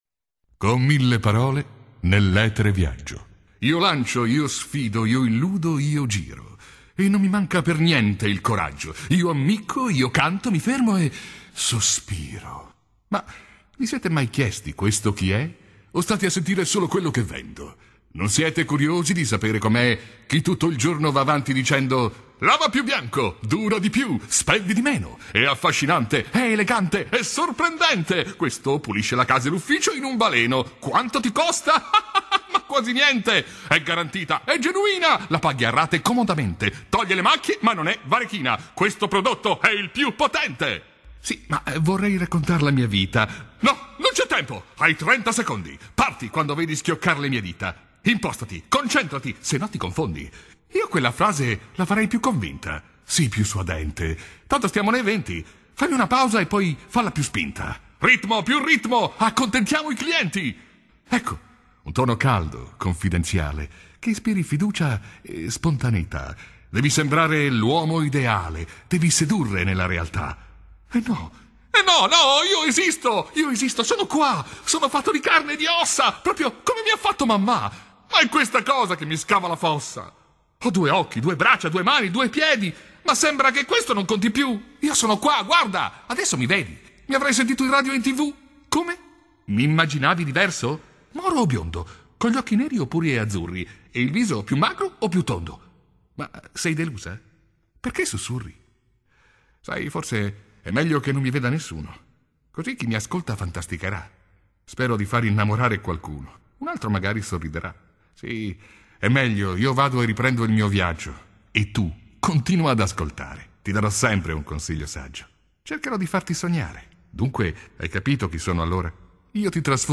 Speaker italiano, esperienza trentennale, voce adattabile a qualsiasi estensione vocale, imitatore, caratterista...
Sprechprobe: Werbung (Muttersprache):